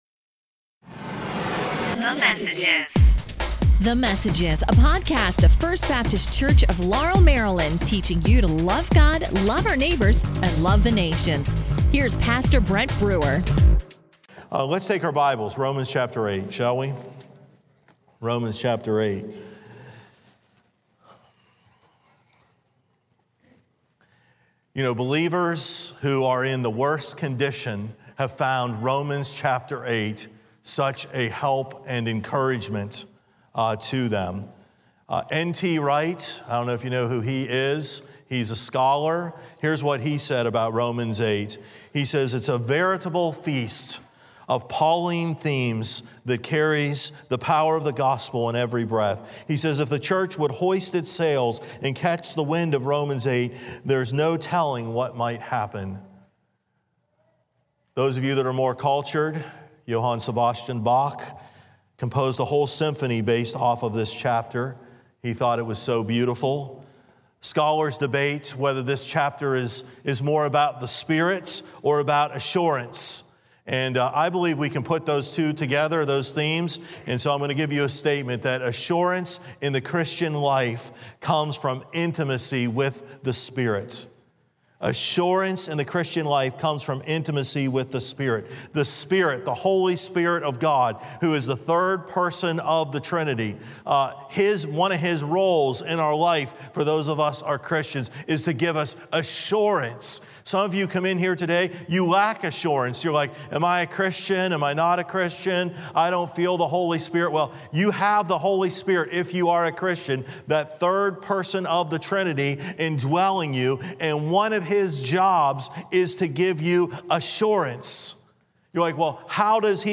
A message from the series "God Rules Over All ."